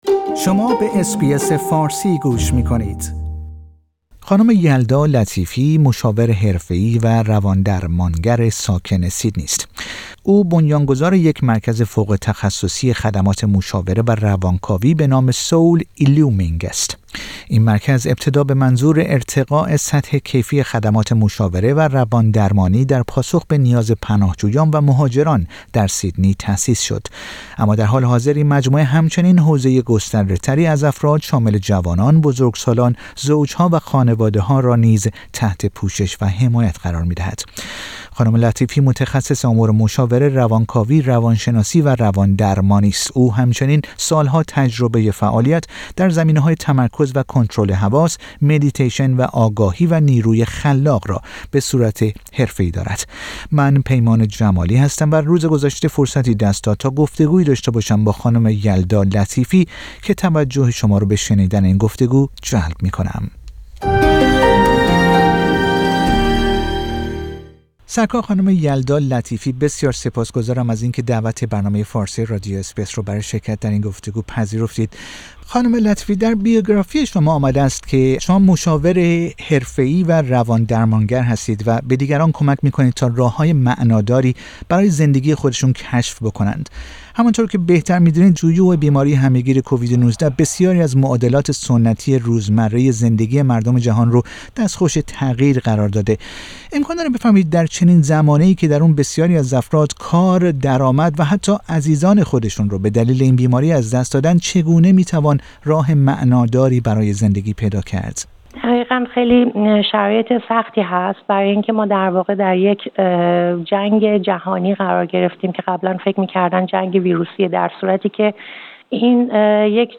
او در گفتگو با رادیو اس بی اس فارسی توضیح می دهد که دوران همه گیری کووید-۱۹ که در آن بسیاری از افراد کار، درآمد و حتی عزیزان خود را به دلیل این بیماری از دست داده اند چگونه می توان راه معناداری برای زندگی پیدا کرد.